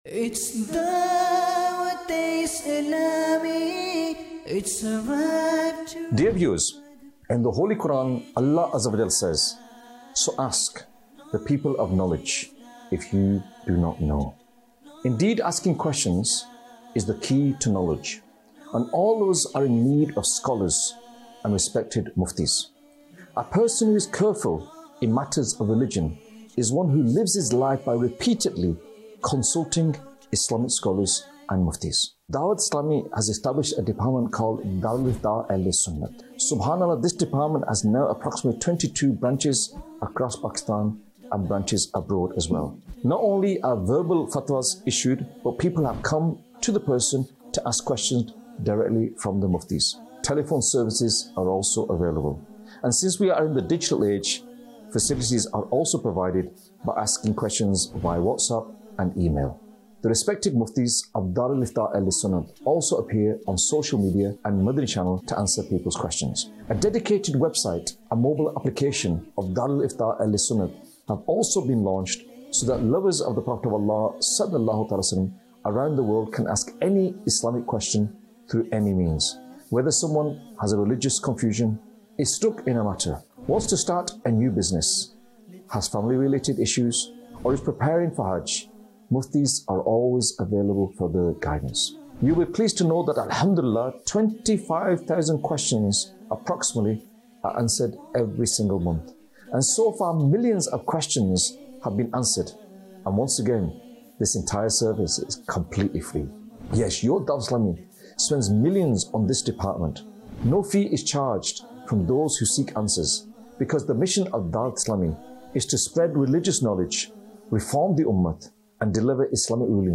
Documentary 2026